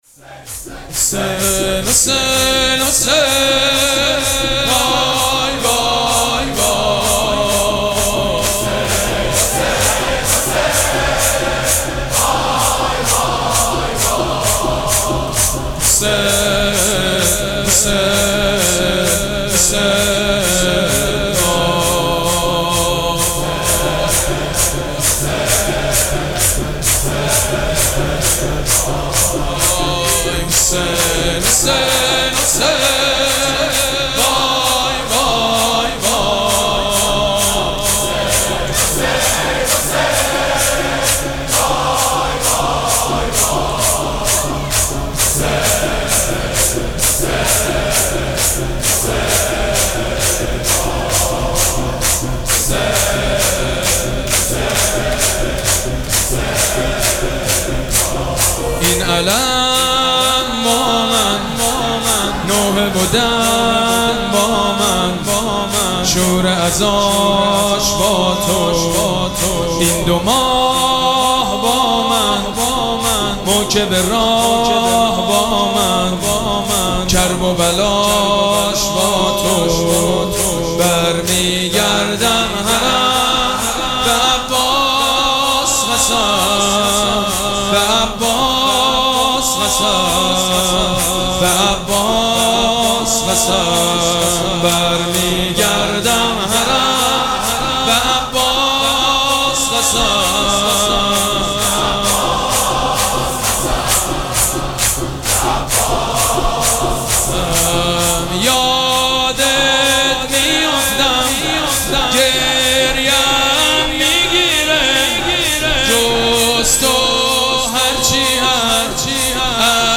مراسم عزاداری شب پنجم محرم الحرام ۱۴۴۷
شور
حاج سید مجید بنی فاطمه